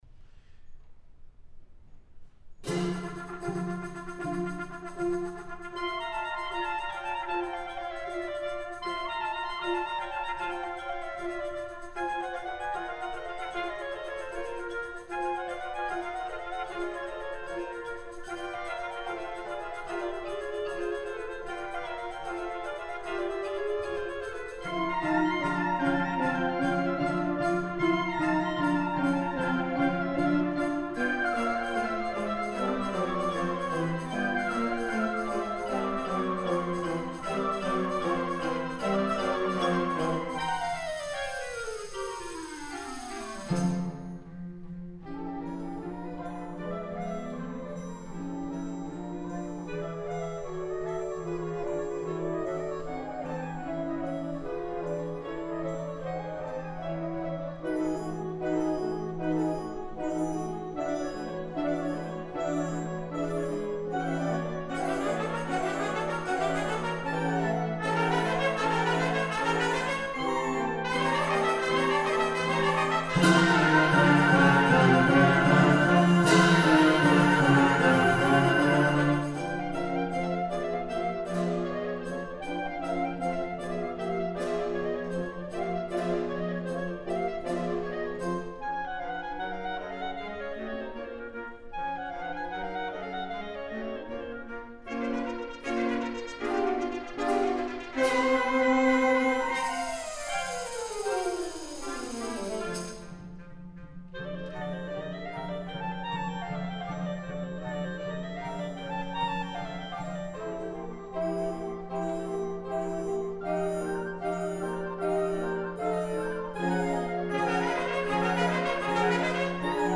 2022 UIL Wind Ensemble — Liberty Band
4:06 Solid Men to the Front Liberty HS Wind Ensemble 2:43 Polovtsian Dances Mvmt 1 Liberty HS Wind Ensemble 12:19 Polovtsian Dances Mvmt 2 Liberty HS Wind Ensemble 9:42 Danzon No. 2 Liberty HS Wind Ensemble